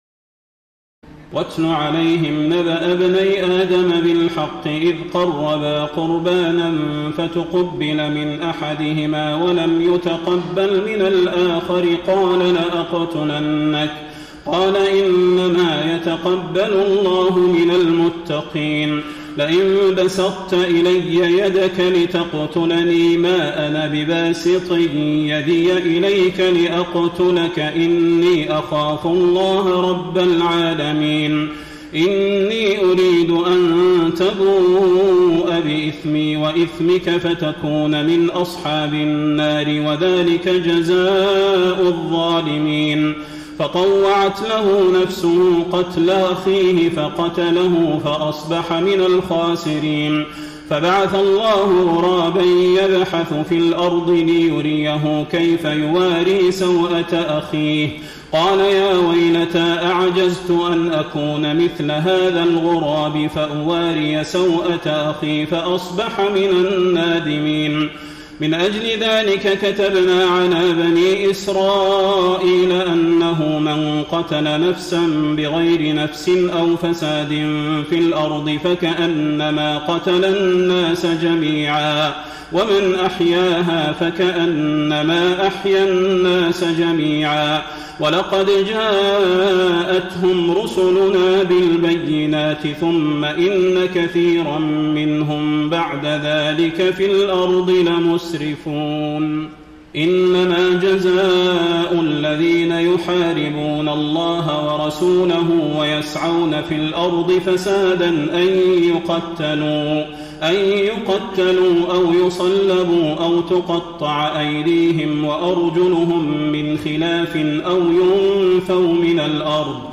تراويح الليلة السادسة رمضان 1433هـ من سورة المائدة (27-81) Taraweeh 6 st night Ramadan 1433H from Surah AlMa'idah > تراويح الحرم النبوي عام 1433 🕌 > التراويح - تلاوات الحرمين